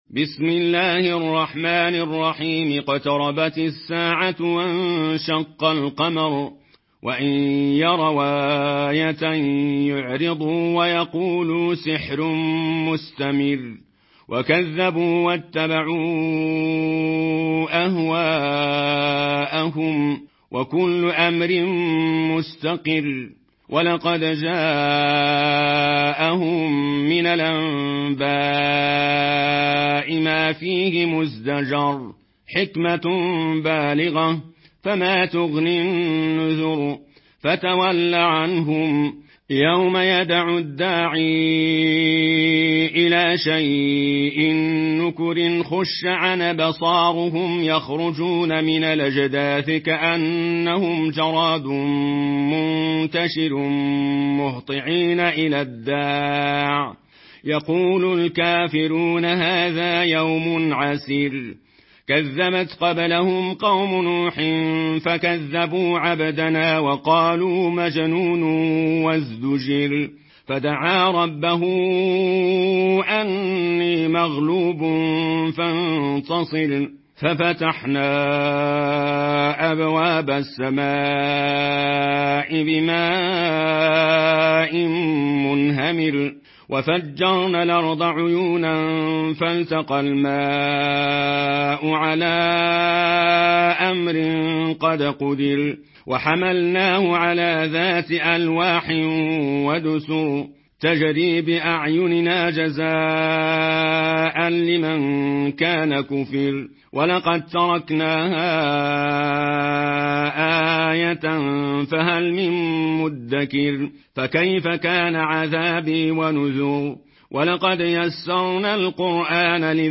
Warsh Narration
Murattal